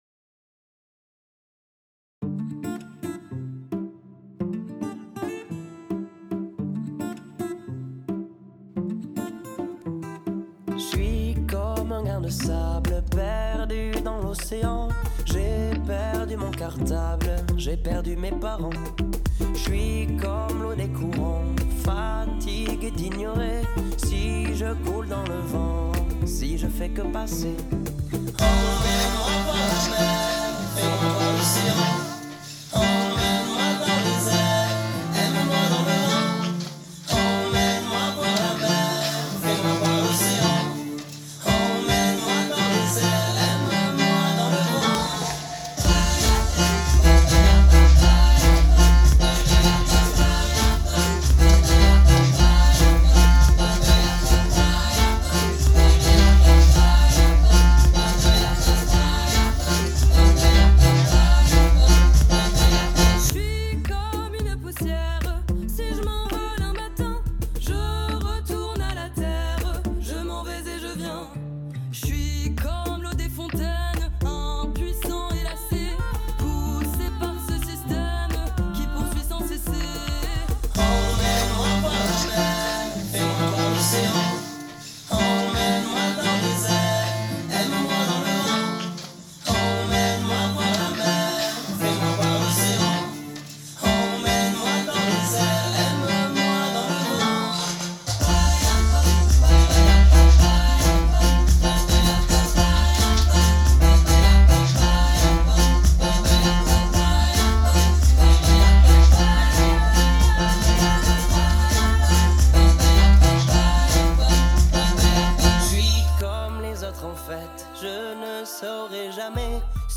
Fichiers pour les Musiques Actuelles :
Emmène-moi avec 2ème Voix
emmenemoiavec2emevoix.mp3